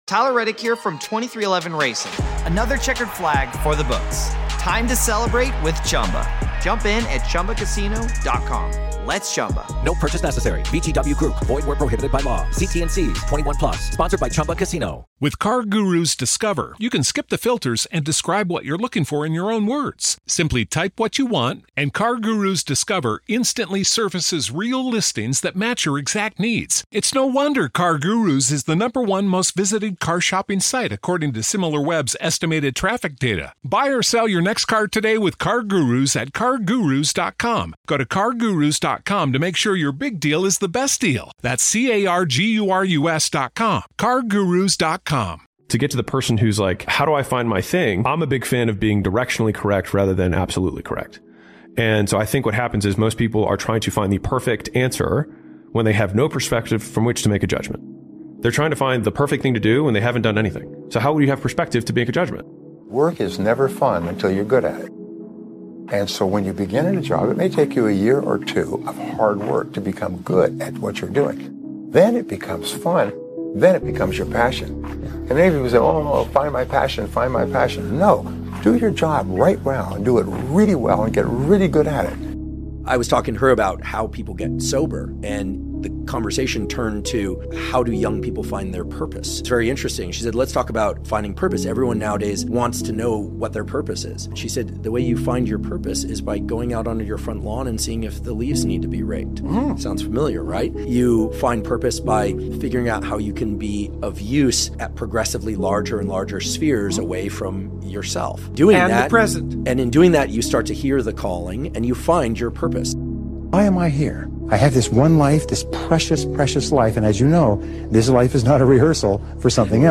BELIEVE IN YOURSELF — YOU WERE BORN FOR MORE | Positive Motivational Speech Compilation